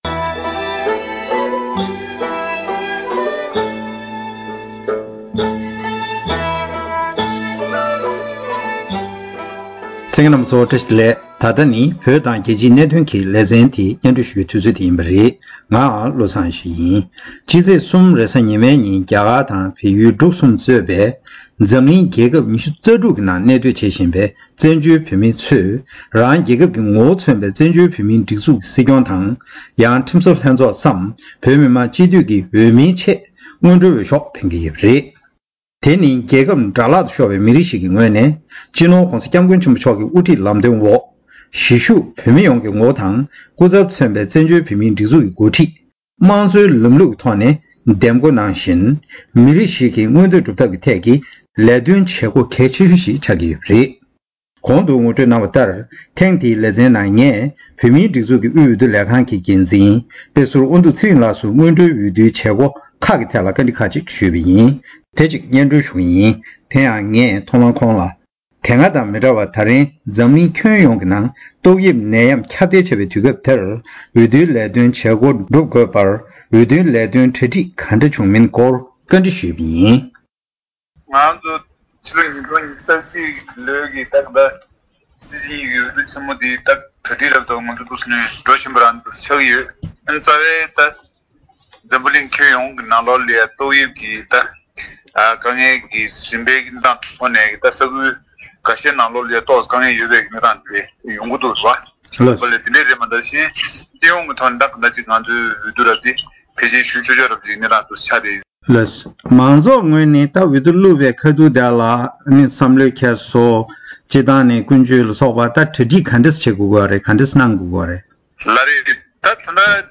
ཞལ་པར་ཐོག་བཅར་འདྲི་ཞུས་པ་དེ་གསན་རོགས་ཞུ།།